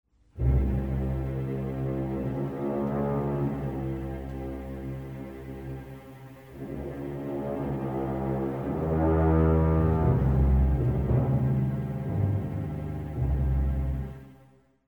Key: V of Db major